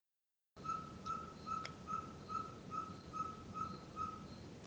Ferruginous Pygmy Owl (Glaucidium brasilianum)
Condition: Wild
Certainty: Recorded vocal